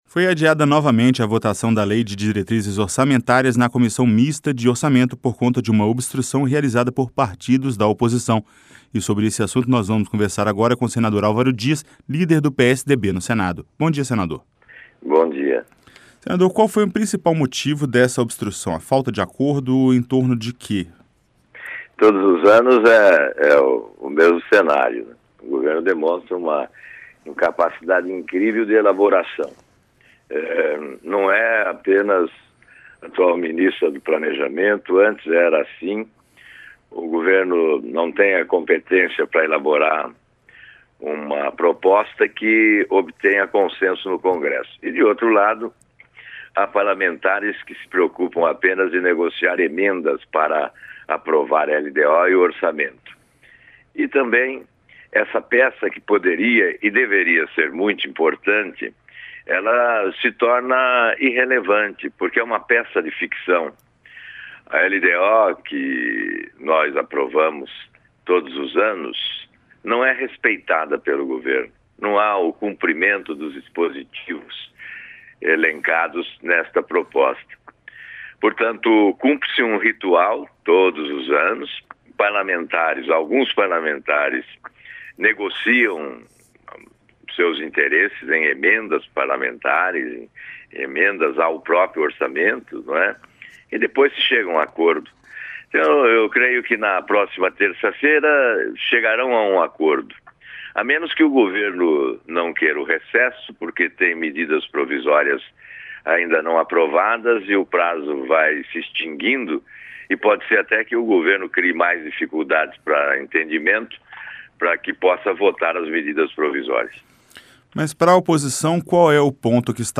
Entrevista com o senador Alvaro Dias (PSDB-PR).